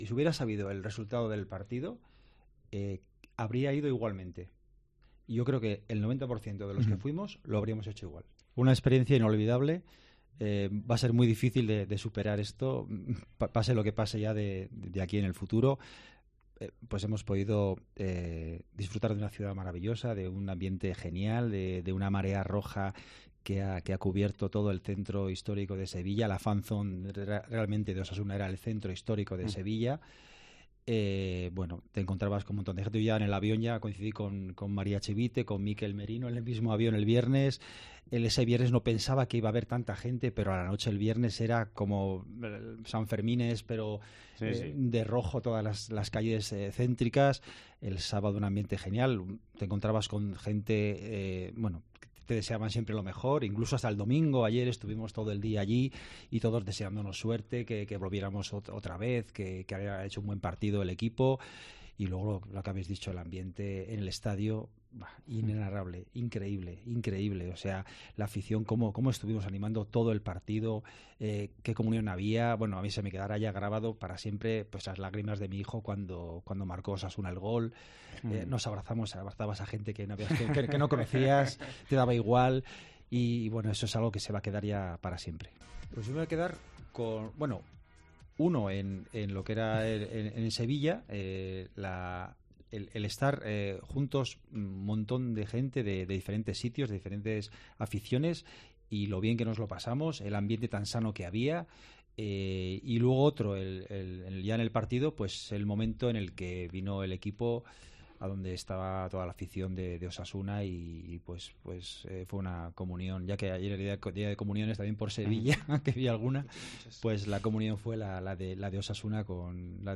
socio de Osasuna